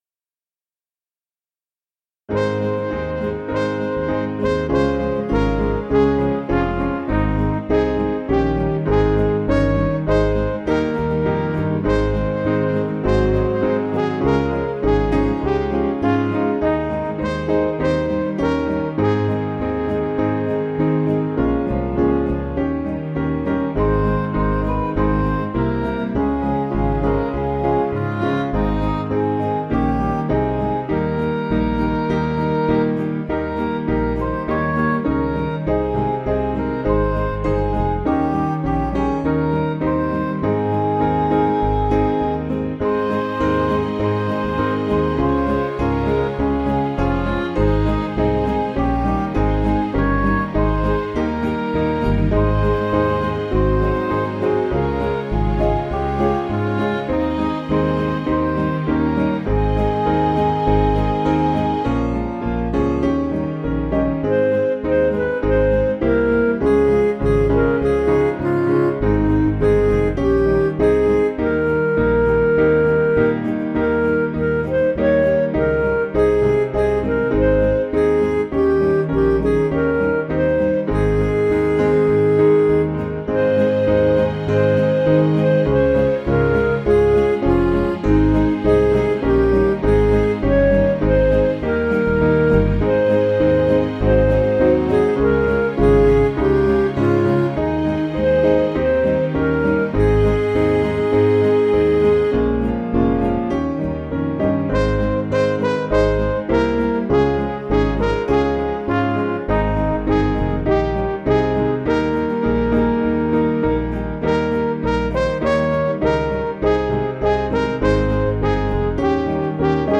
Piano & Instrumental
(CM)   3/Ab
Midi